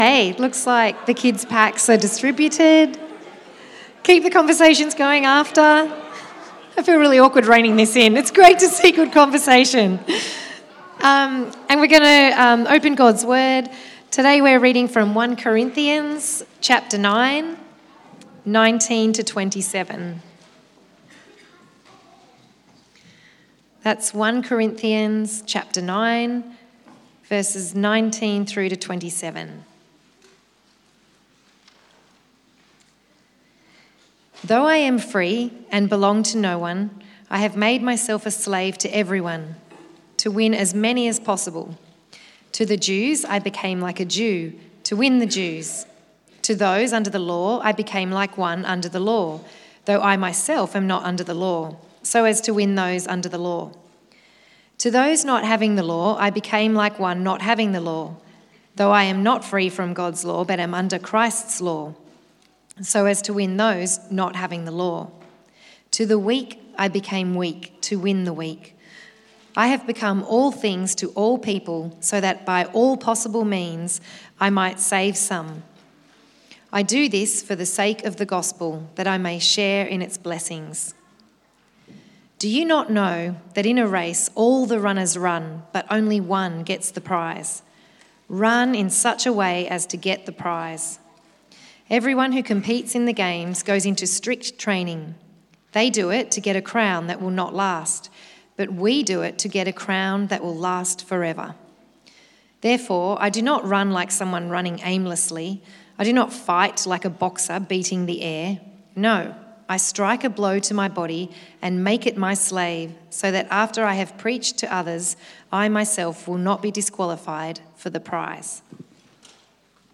1 Corinthians 9:1-18 Service Type: 4PM This Sunday